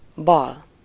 Ääntäminen
IPA : /ˈlɛft/